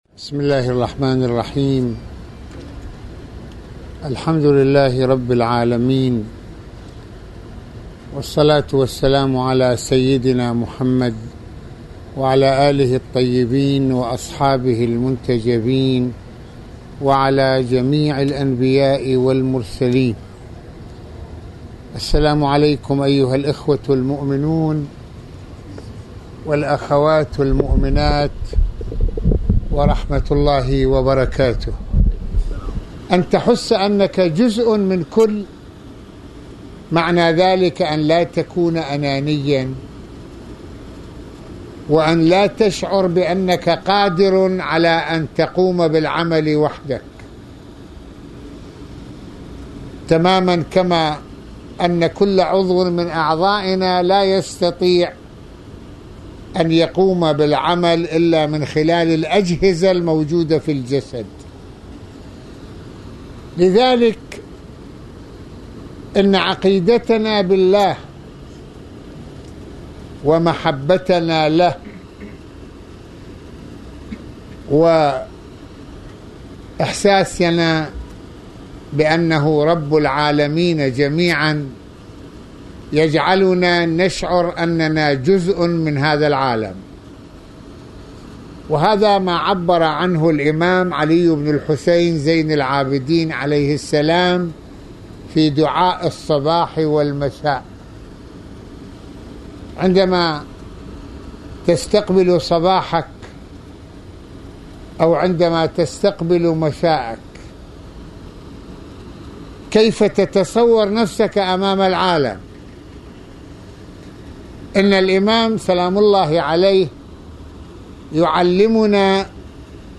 - يتحدّث العلامة المرجع السيّد محمد حسين فضل الله(رض) في هذه المحاضرة عن الأنانية عند البعض متناسين أن الإنسان بطبيعته محتاج لغيره لقيامه بأي عمل، وأنه جزء من النظام الكوني، ويتناول سماحته(رض) بعض ما ورد من معاني في أدعية الإمام السجّاد (ع) تتصل بشعورنا على أننا جزء من هذا العالم وعلاقتنا به..